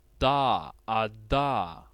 [d]
English dad
Voiced_alveolar_plosive.ogg.mp3